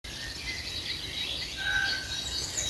UCCELLI
Buongiorno, sapreste dirmi che specie è che fa questo strano verso che si sente verso la fine del breve audio? lo ha ripetuto più volte. Questa mattina a Cuneo, in zona boschiva lungo il fiume (pianura, 450 m ca.).